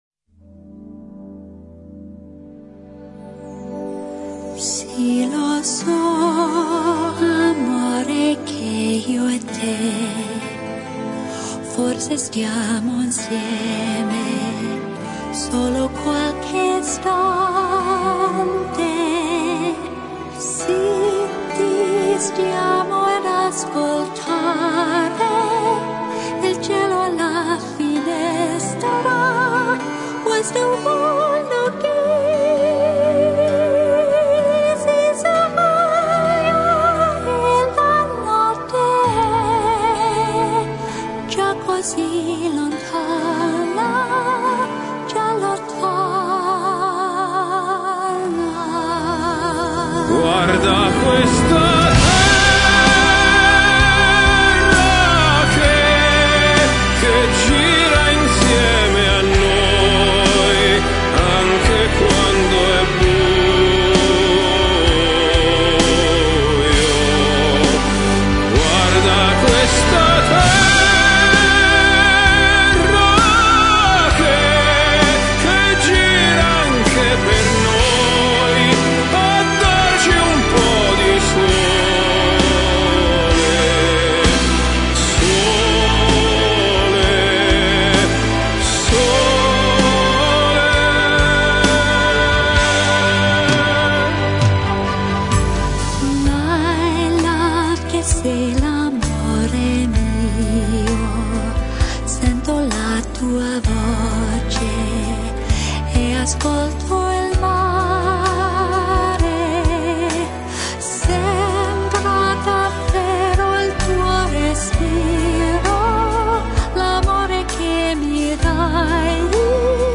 Classicas